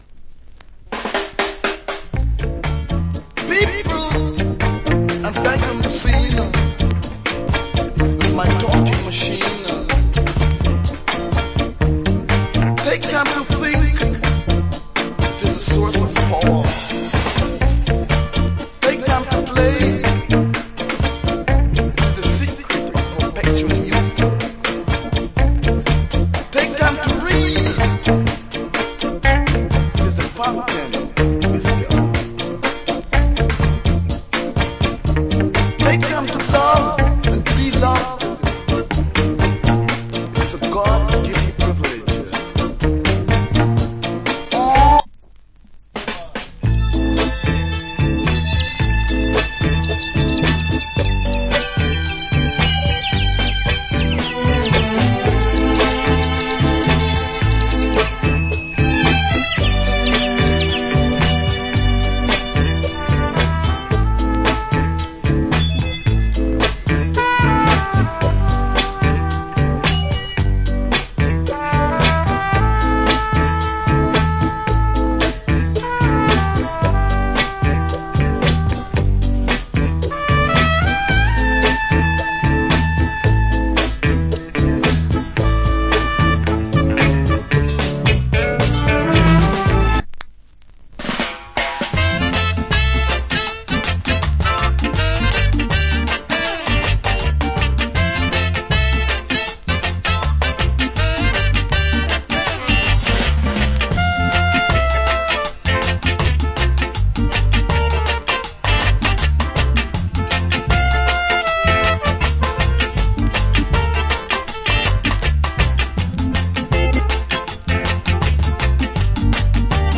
Reggae Inst.